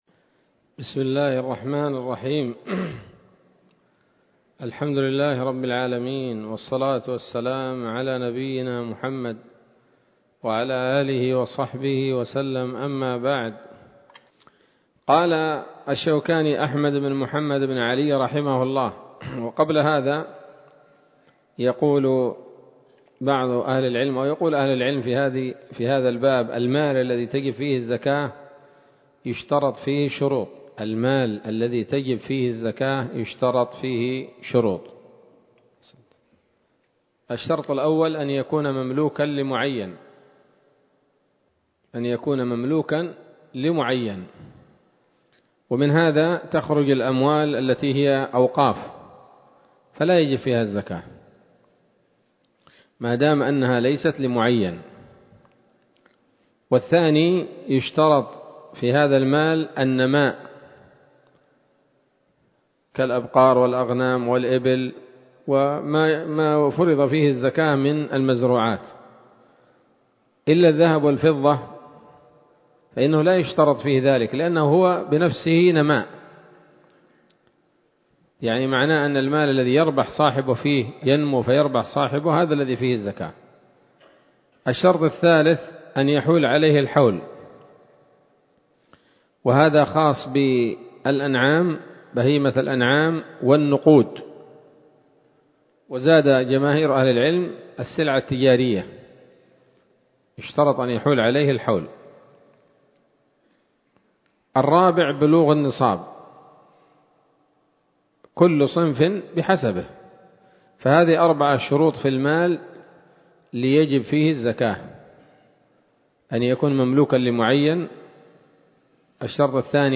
الدرس الثاني من كتاب الزكاة من السموط الذهبية الحاوية للدرر البهية